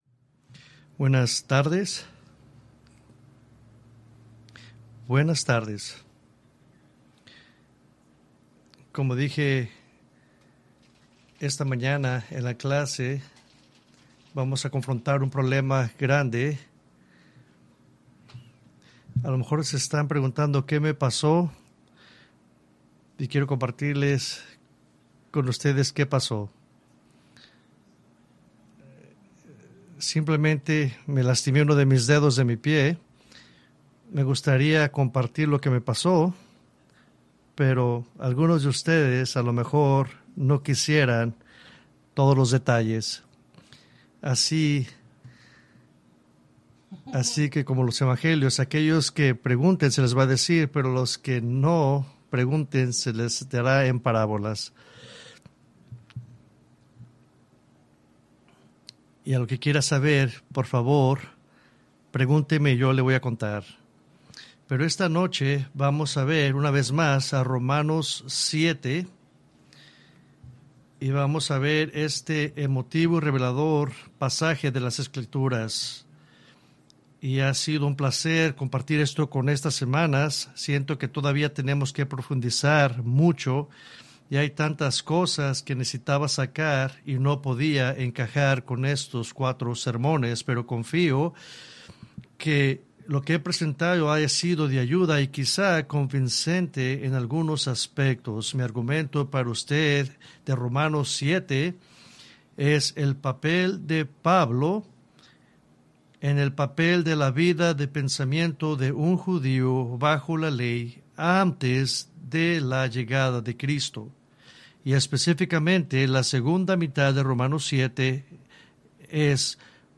Preached July 21, 2024 from Romans 7